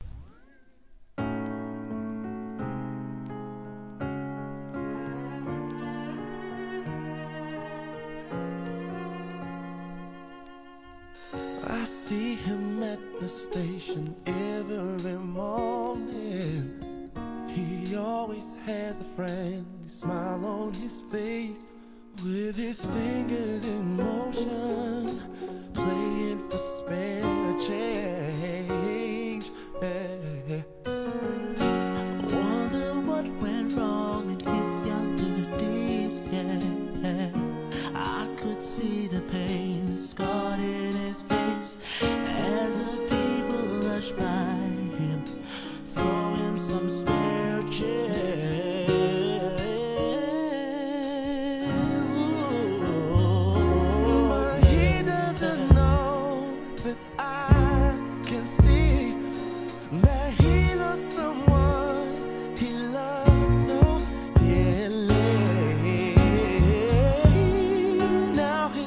素晴らしいコーラスが堪能できる1999年 UK SOUL!